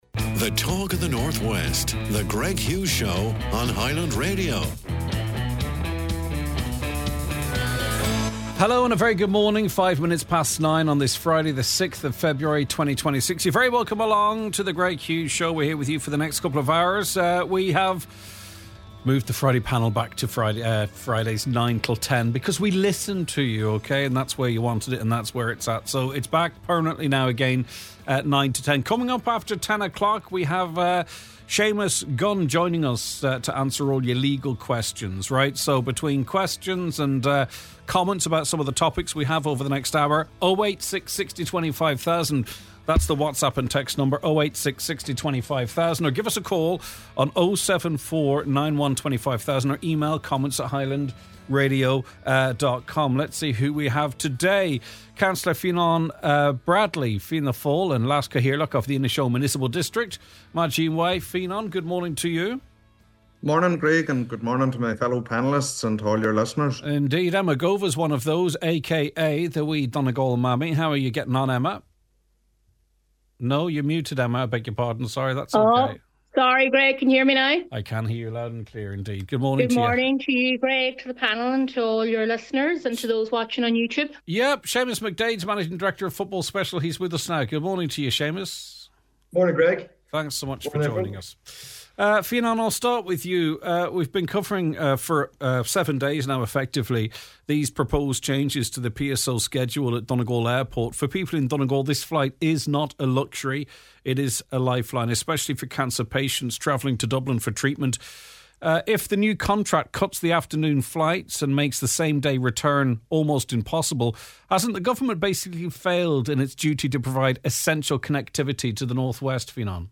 Deputy Pearse Doherty joins the program to discuss the proposed changes to the Public Service Obligation (PSO) contract, which could see the loss of the vital afternoon flight.